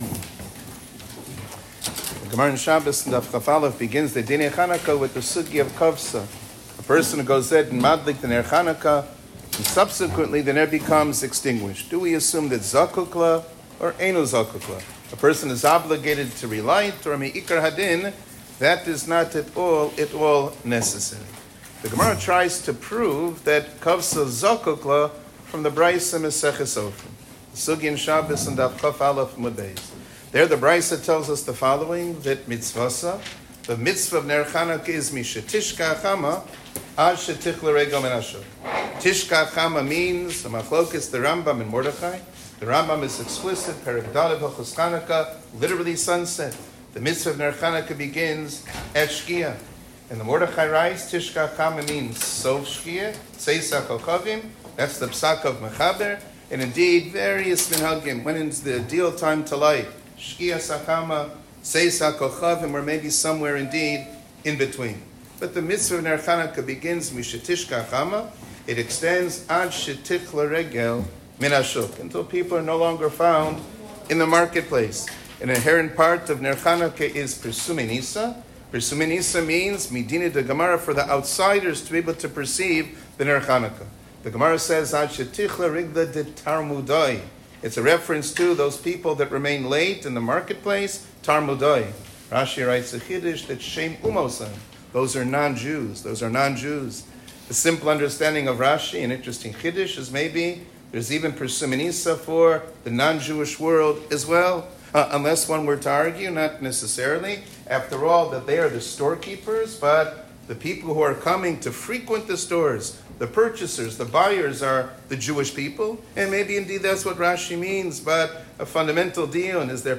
שיעור כללי - זמן הדלקת נר חנוכה